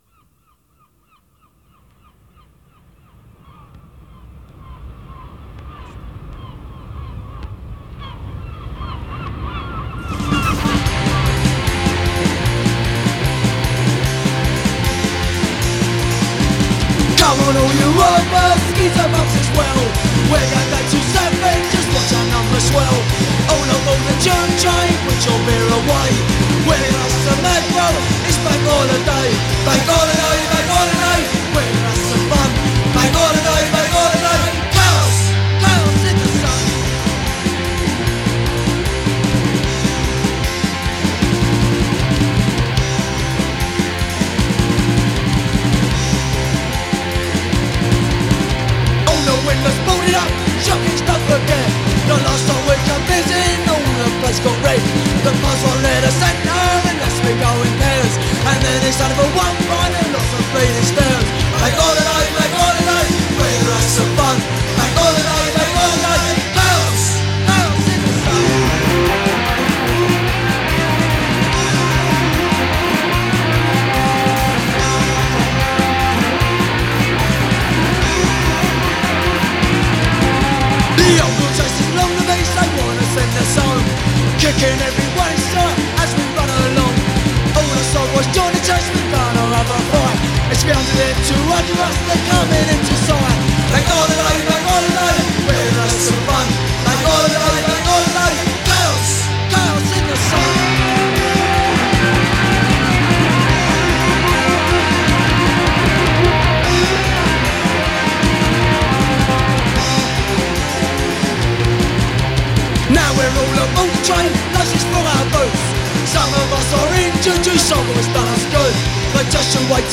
klassischen Oi! mit der nötigen Härte